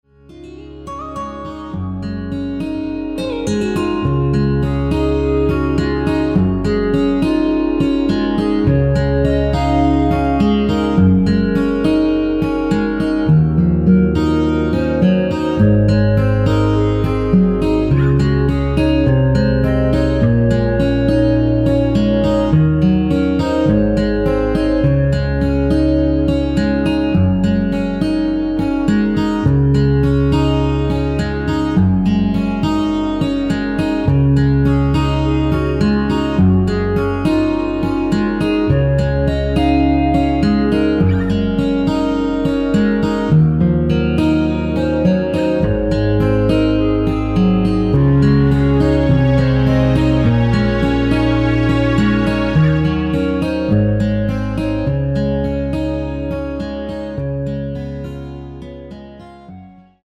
(-1) 내린 1절 편곡 멜로디 포함된 MR 입니다.
Db
◈ 곡명 옆 (-1)은 반음 내림, (+1)은 반음 올림 입니다.
앞부분30초, 뒷부분30초씩 편집해서 올려 드리고 있습니다.